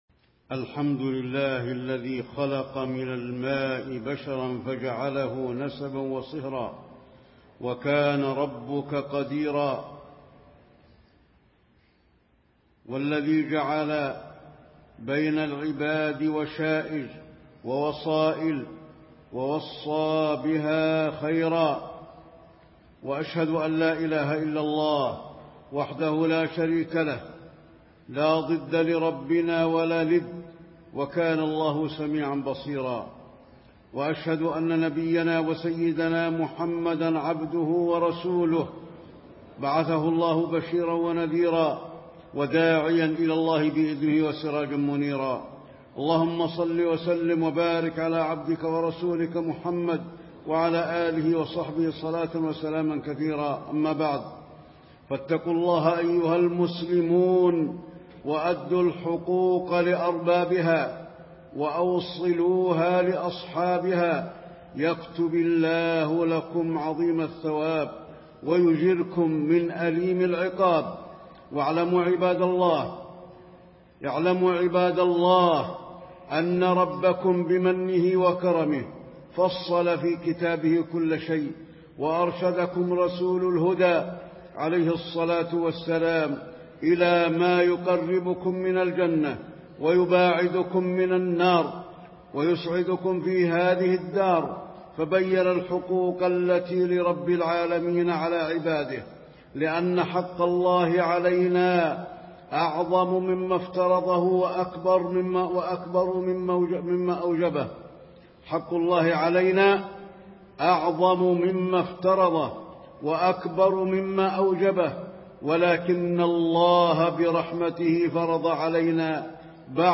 تاريخ النشر ١٤ ذو القعدة ١٤٣٤ هـ المكان: المسجد النبوي الشيخ: فضيلة الشيخ د. علي بن عبدالرحمن الحذيفي فضيلة الشيخ د. علي بن عبدالرحمن الحذيفي فضل صلة الرحم